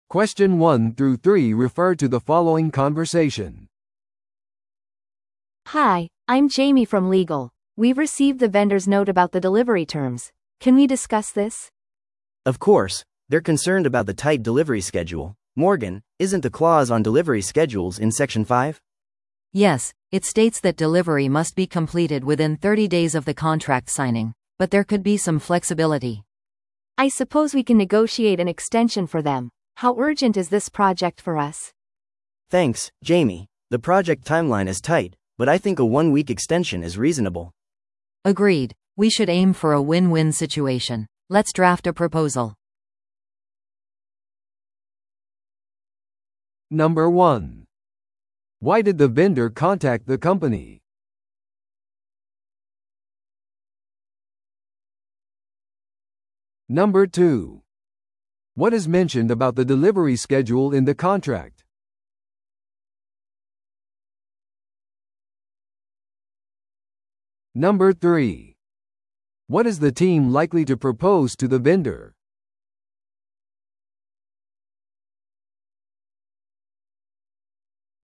TOEICⓇ対策 Part 3｜契約納期延長交渉について – 音声付き No.238